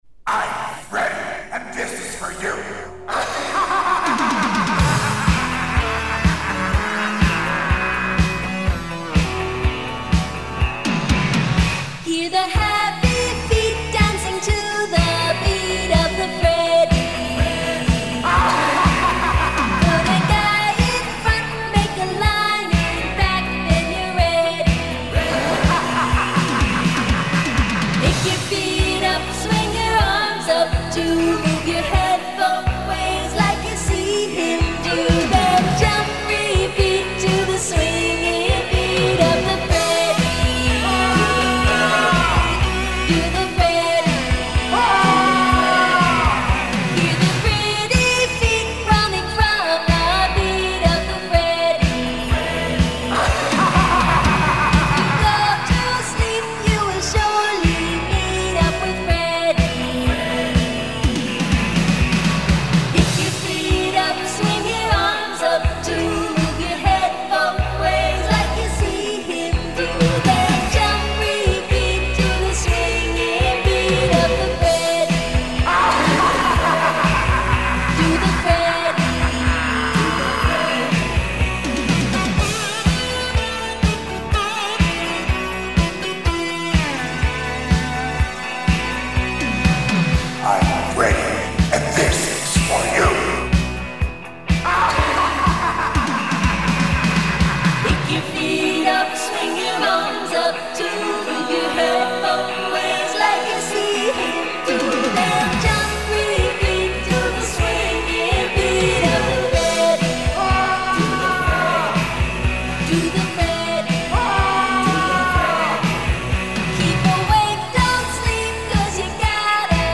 you have an awful dance album
generic pop singers
an utterly cheesy novelty dance party album